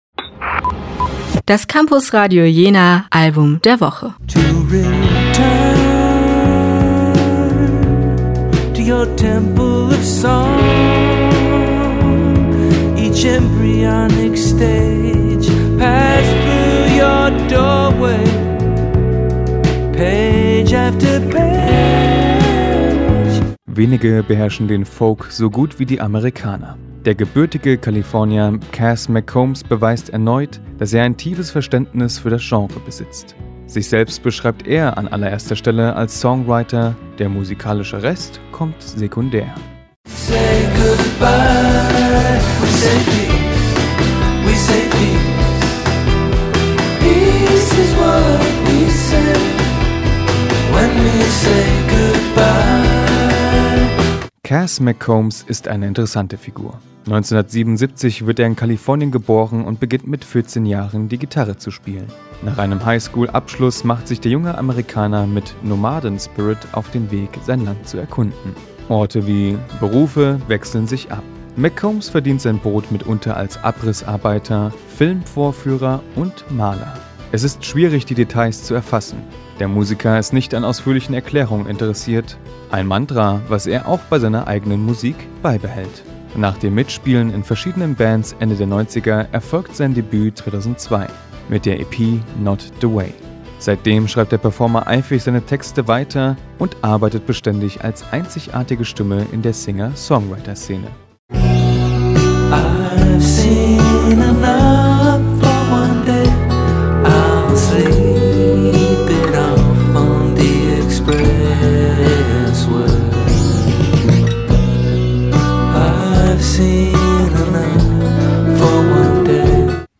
Nachdenklich grübelnd und doch noch von Optimismus mitgeprägt – das ist “Interior Live Oak” von Cass McCombs! Gekonnt gemachter Folk-Rock und unser Campusradio Jena Album der Woche.